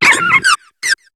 Cri de Rozbouton dans Pokémon HOME.